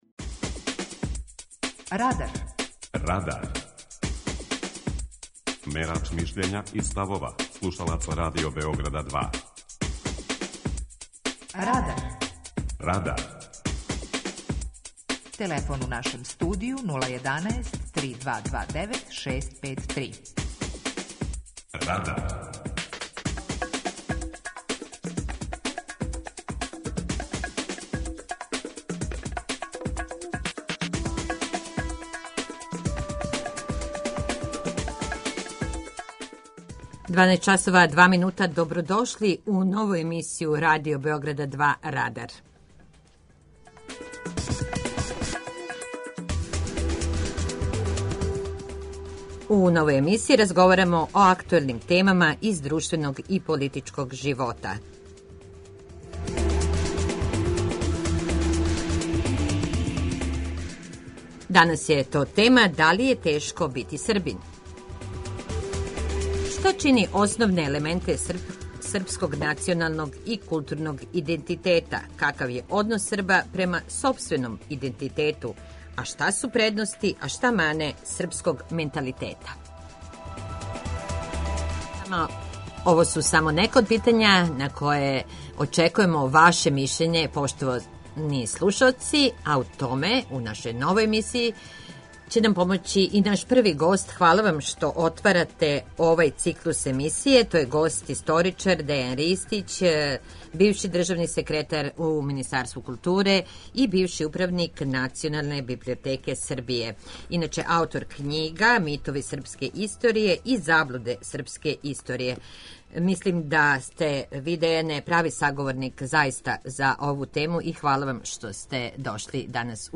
Нову емисију Радар, у којој ће гости и слушаоци Радио Београда 2 разговарати о актуелним темама из друштвеног и политичког живота, почињемо темом ‒ Да ли је тешко бити Србин?